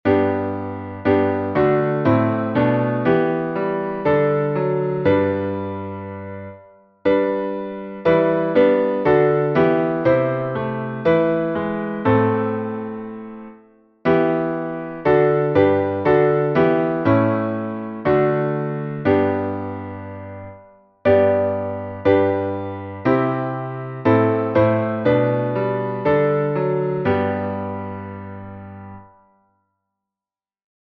Salmo 134A Métrica: 8 8. 8 8
Modo: hipojônio
salmo_134A_instrumental.mp3